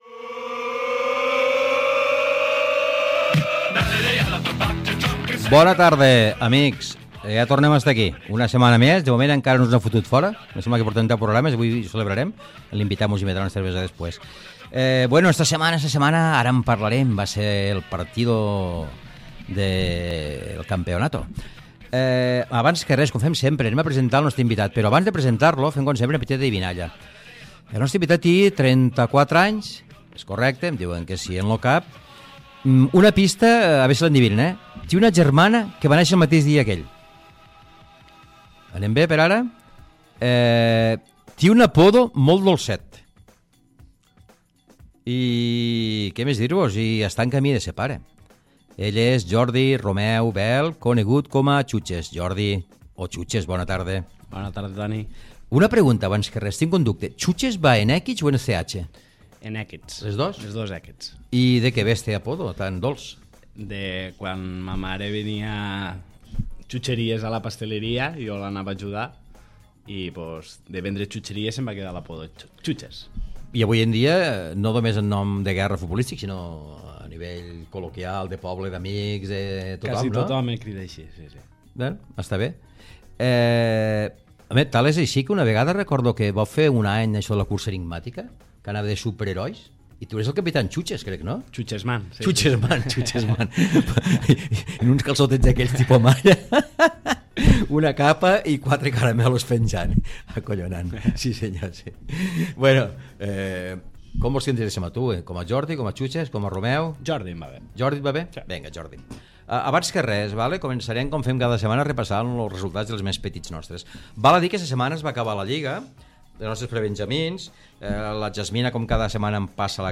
Esportiu
FM